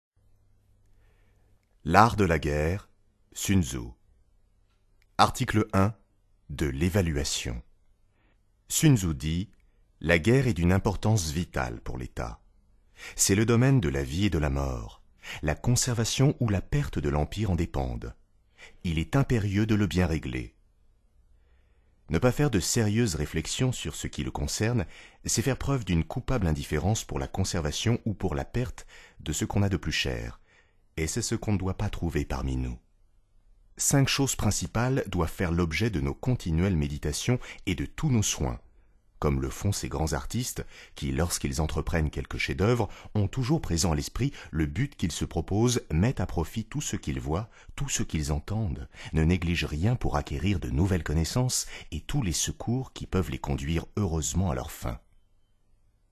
Click for an excerpt - L'art de la guerre de Sun Tzu